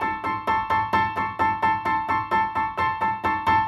Index of /musicradar/gangster-sting-samples/130bpm Loops
GS_Piano_130-A1.wav